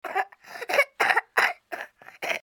Звук кашляющего простуженного ребенка